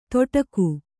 ♪ toṭaku